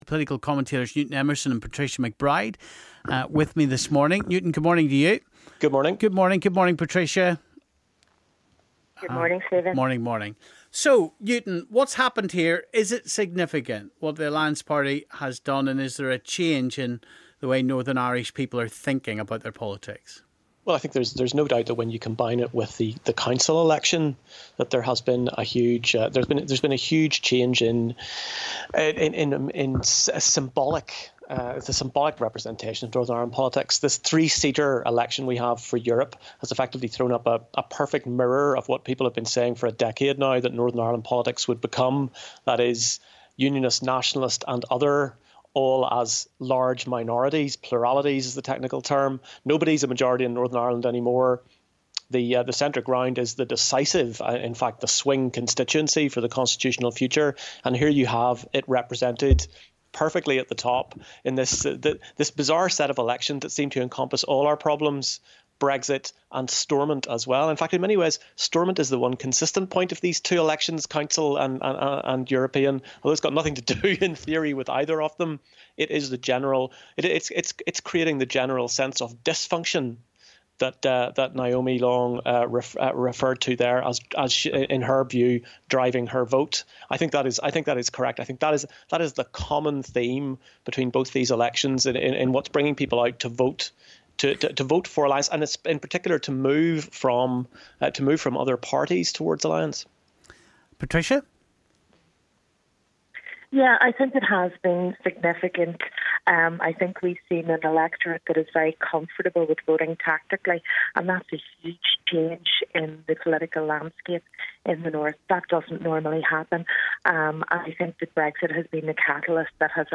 Political commentators